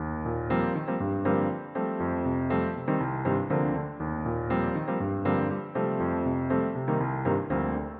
爵士乐仅钢琴2
描述：爵士乐循环与钢琴
Tag: 120 bpm Jazz Loops Piano Loops 1.35 MB wav Key : Unknown